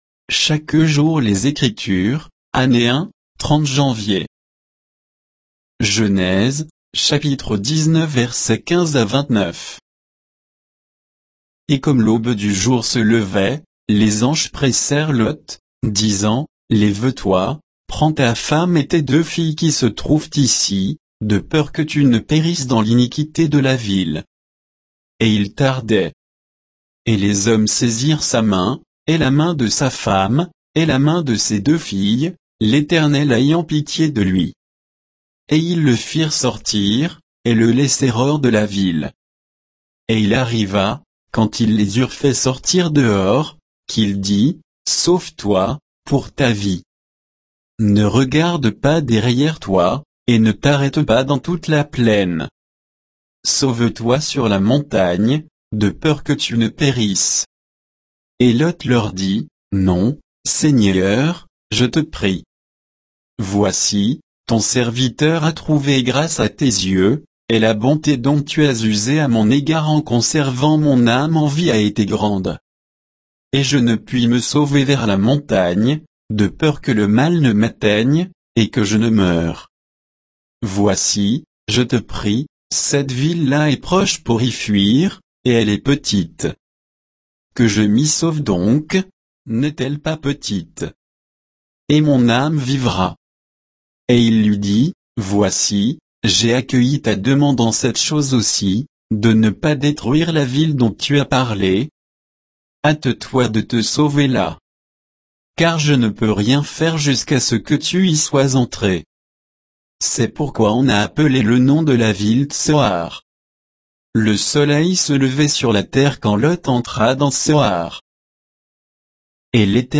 Méditation quoditienne de Chaque jour les Écritures sur Genèse 19